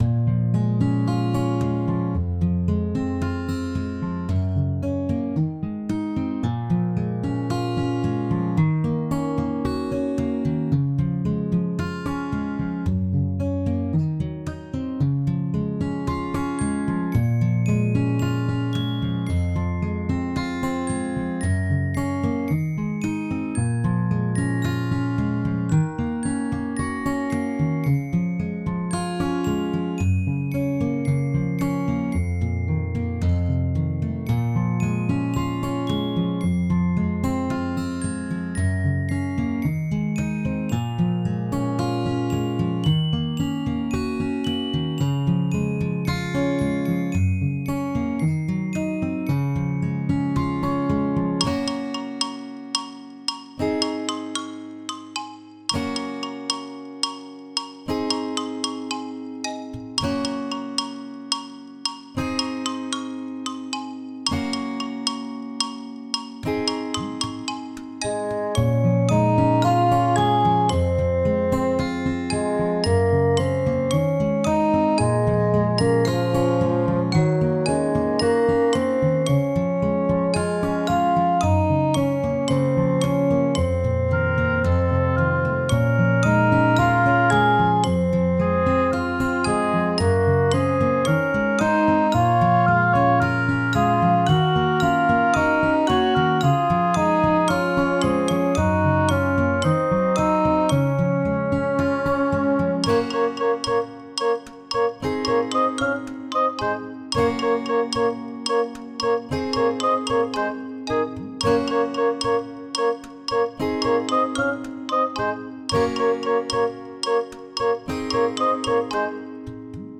フリーBGM素材- 秋ってかんじの森。